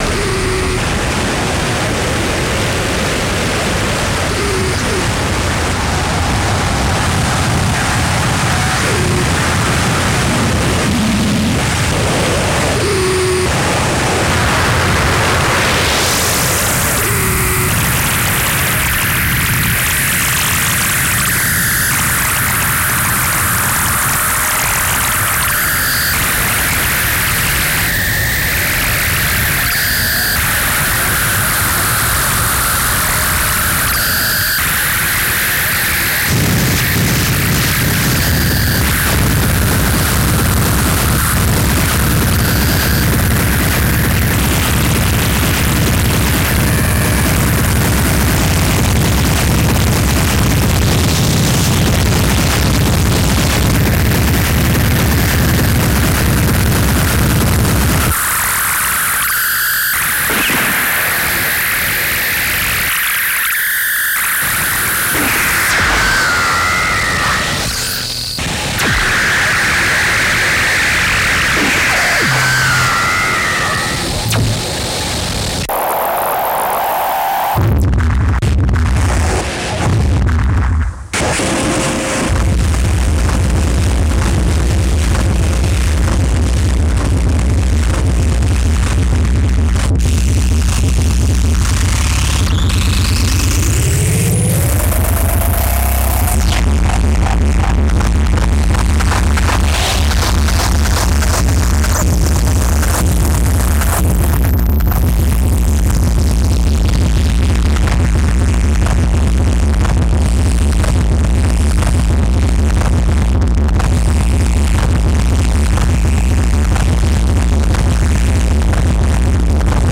• Genre: Japanese Noise